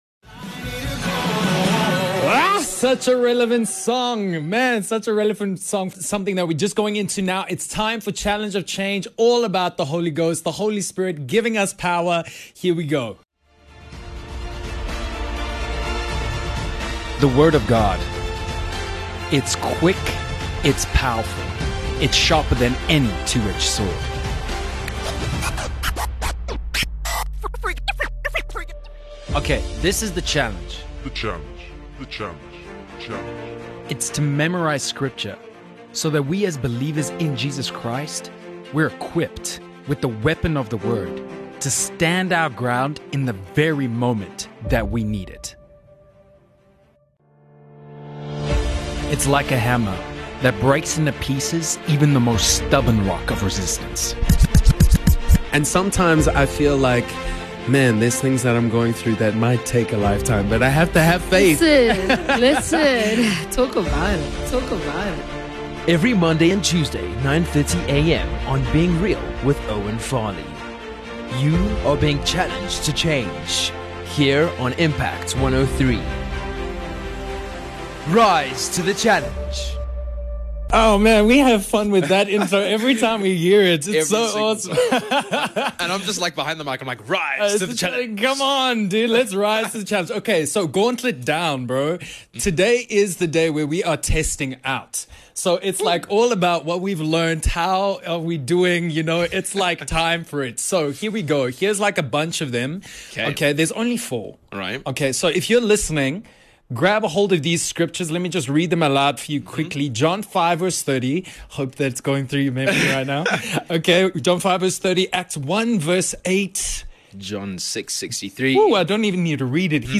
Test yourself out with the guys in studio! The challenge is to memorize scripture so that we are equipped in the very moment that we need it.
Filled with laughs, testimonies and the Word of God.